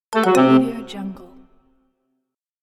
Not_Enough_Coins_Gems_1.mp3